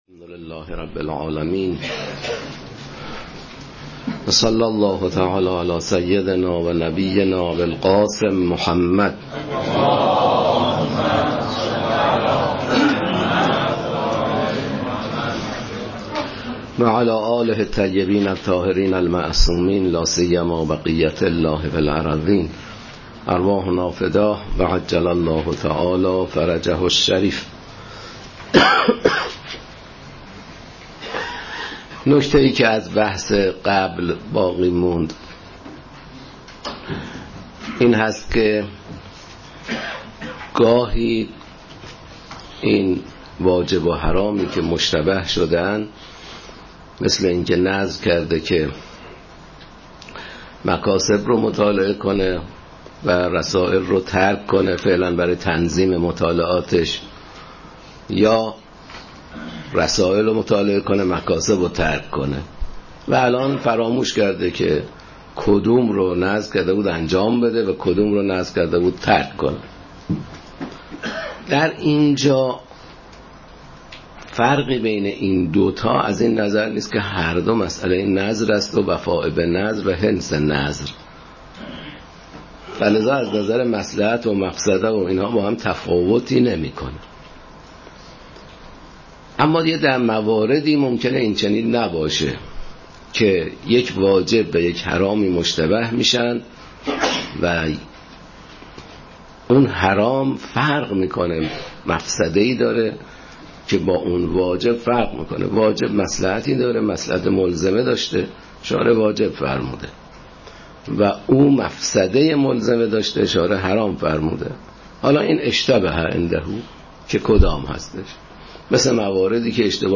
لازم به ذکر است: متن ذیل پیاده شده از صوت درس می‌باشد و هیچگونه ویرایشی روی آن اعمال نشده است.